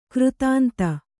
♪ křtānta